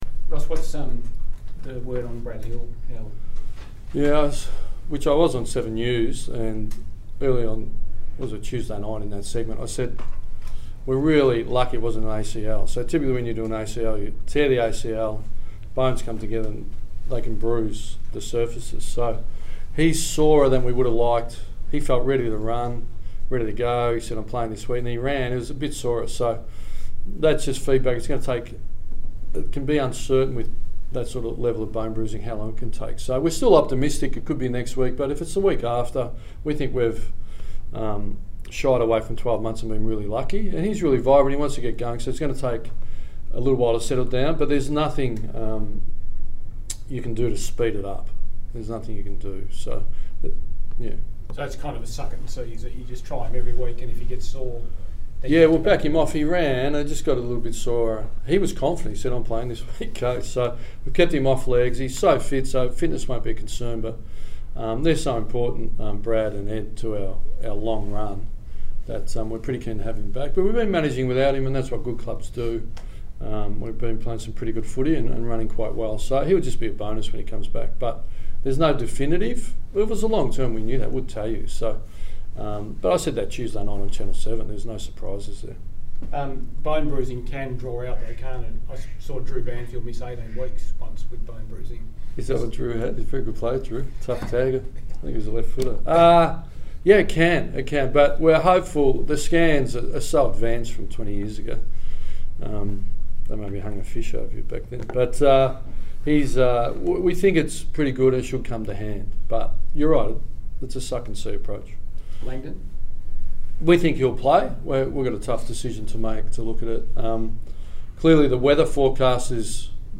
Ross Lyon media conference - Thursday 19 April 2018
Ross Lyon spoke to the media ahead of the round five clash with the Western Bulldogs